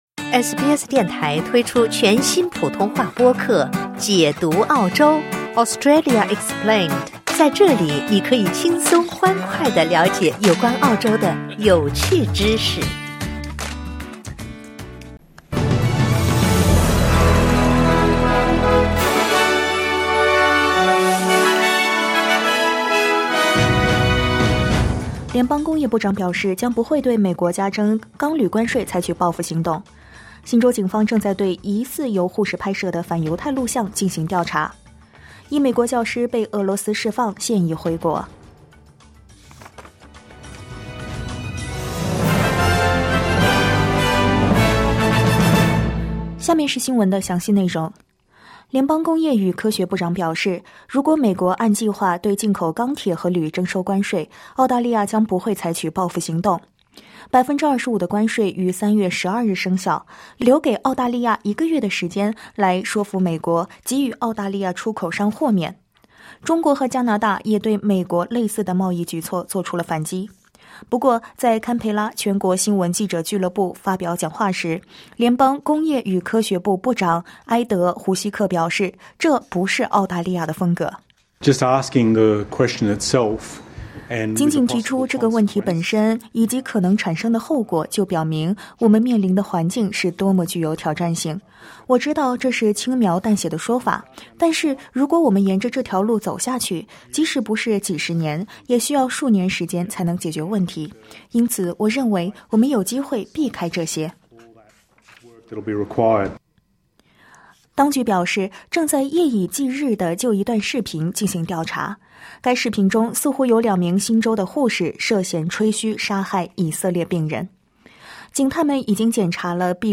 SBS早新闻（2025年2月13日）